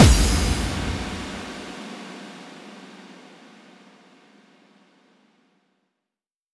VEC3 FX Reverbkicks 13.wav